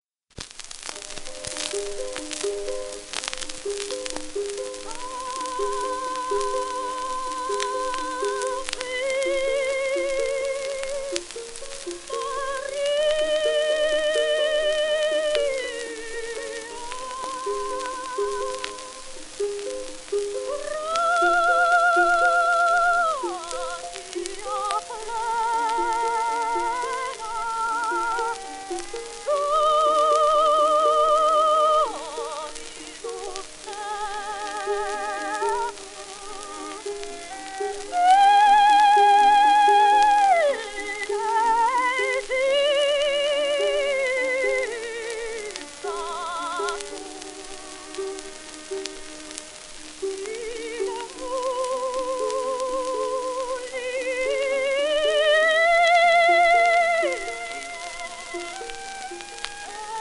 w/オーケストラ
80rpm
1910年録音
ロシア出身のソプラノ。
旧 旧吹込みの略、電気録音以前の機械式録音盤（ラッパ吹込み）